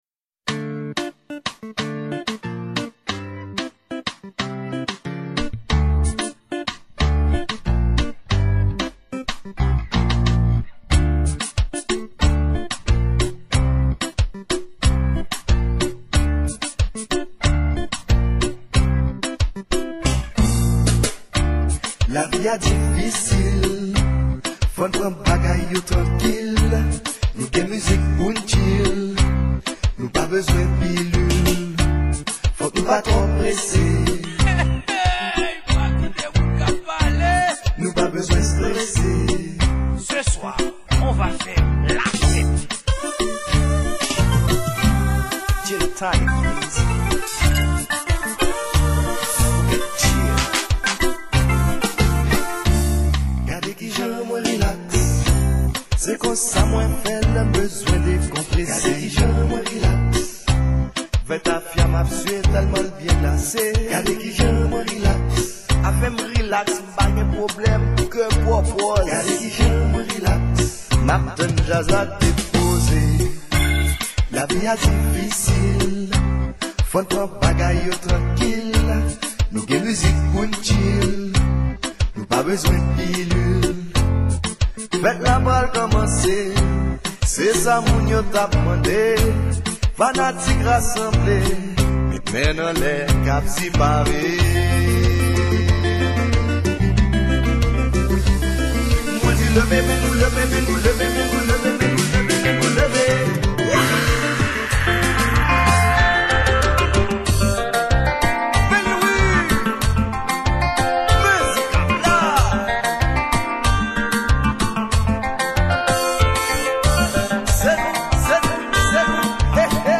Genre: Konpa